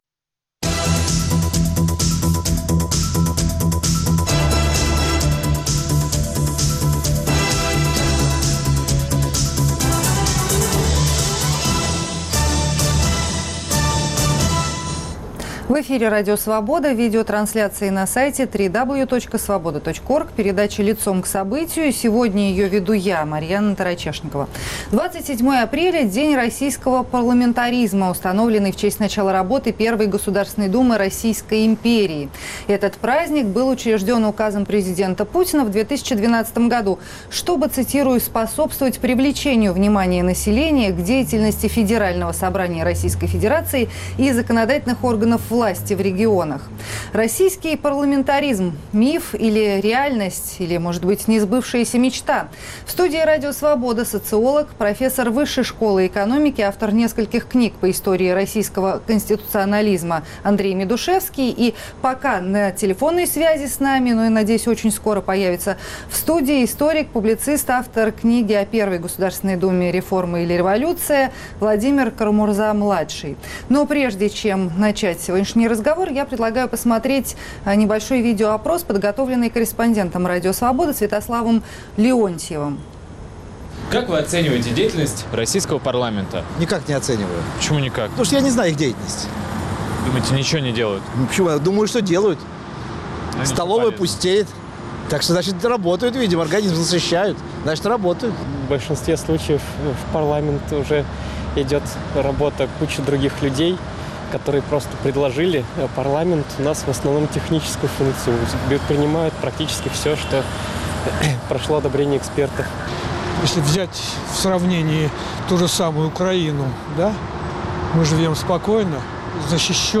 Российский парламентаризм — миф, реальность или несбывшаяся мечта? В студии Радио Свобода социолог